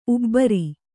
♪ ubbari